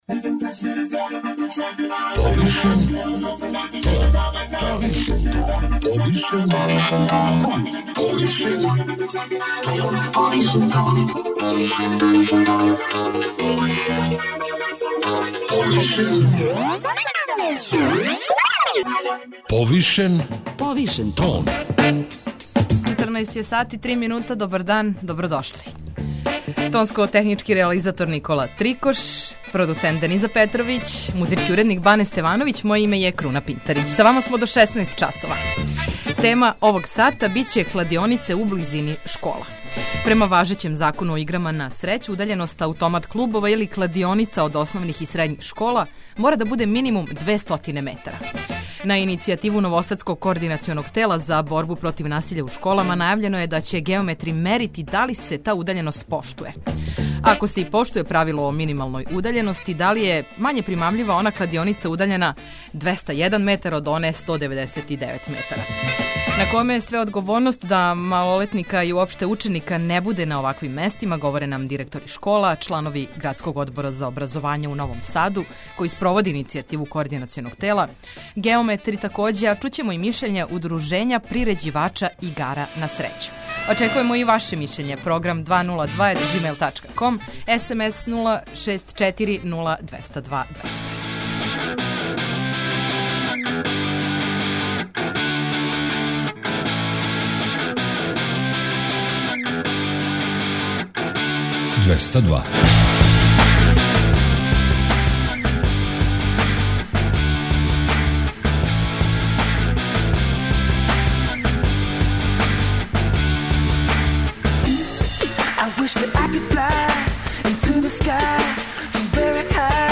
На коме је све одговорност да малолетника и уопште ученика не буде на оваквим местима, говоре нам директори школа, чланови Градског одбора за образовање у Новом Саду, који спроводе иницијативу Коорд. тела, геометри, а чућемо и мишљење Удружења приређивача игара на срећу.